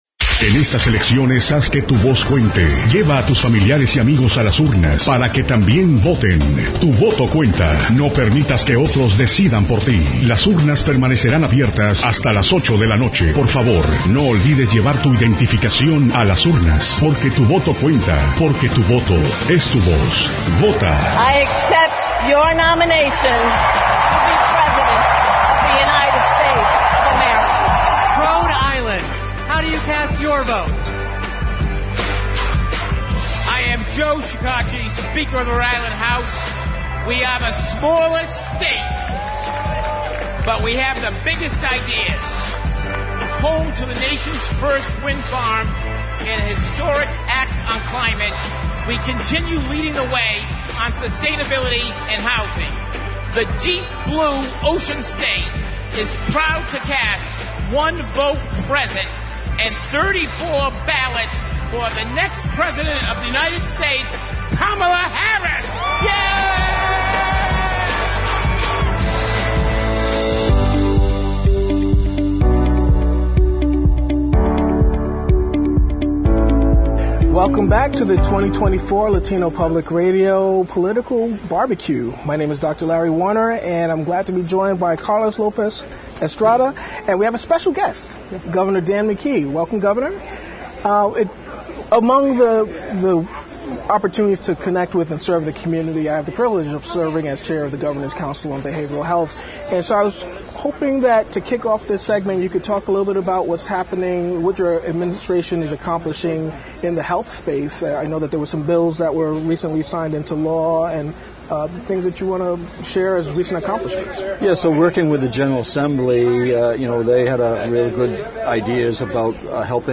RI Governor Dan McKee at LPR’s Political BBQ 2024